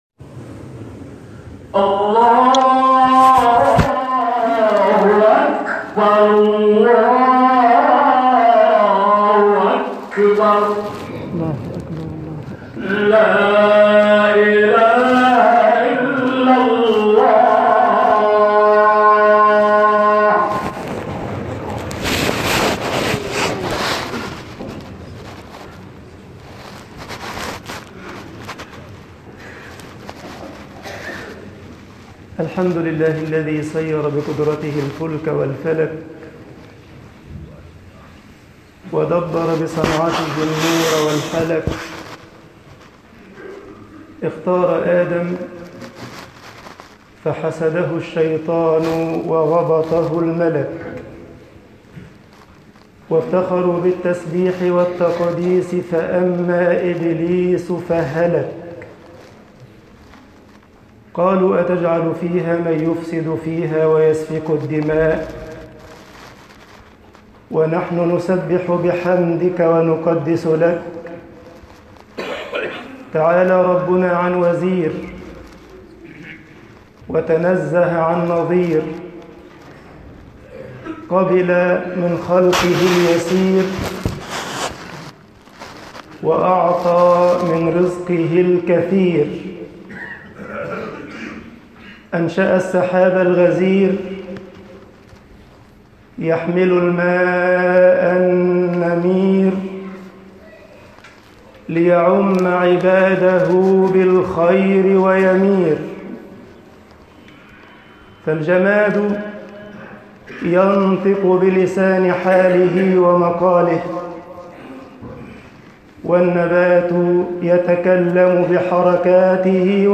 خطب الجمعة - مصر